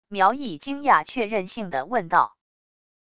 苗毅惊讶确认性地问道wav音频